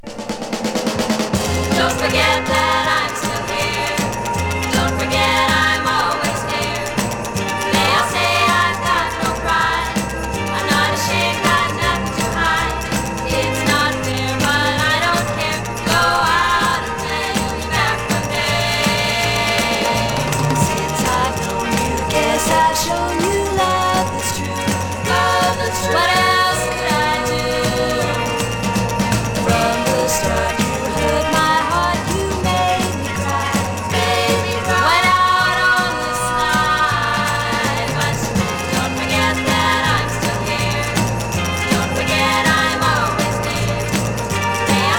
Pop, Girl　USA　12inchレコード　33rpm　Mono